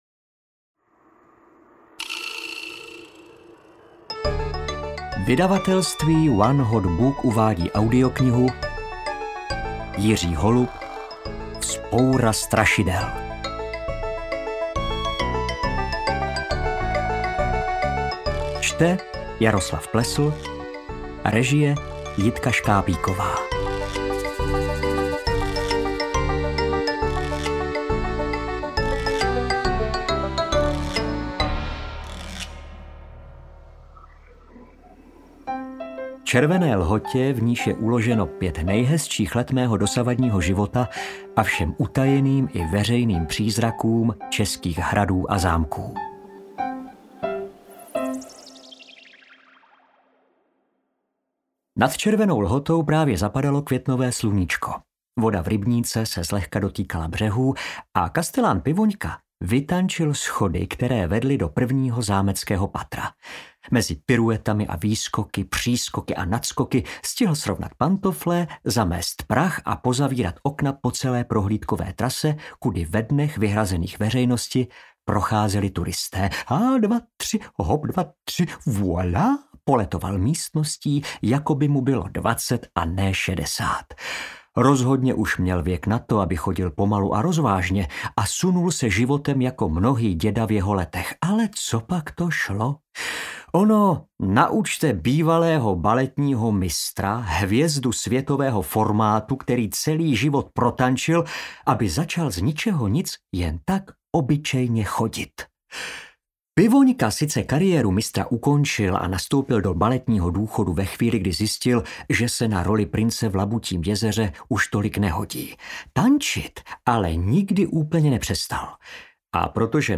Interpret:  Jaroslav Plesl
Tady je od základu všechno skvělé, od textu, přeš hudbu po interpreta.
AudioKniha ke stažení, 13 x mp3, délka 4 hod. 2 min., velikost 222,6 MB, česky